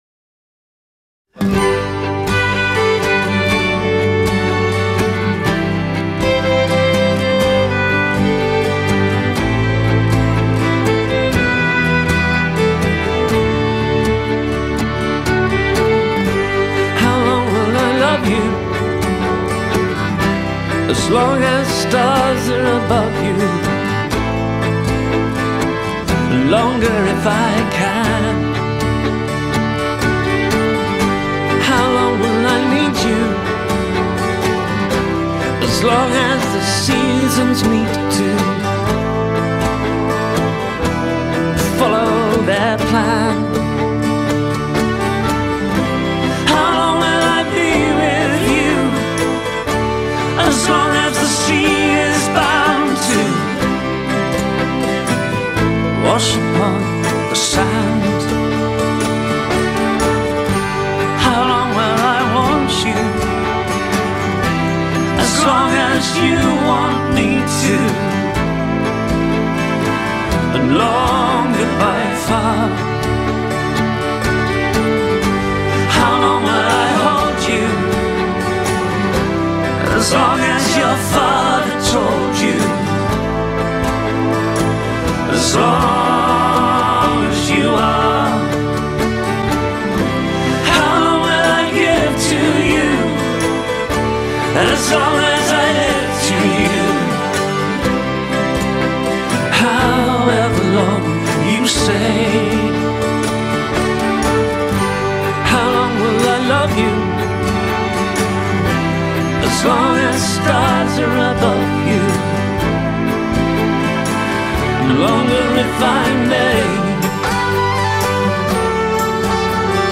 Довольно романтичная песня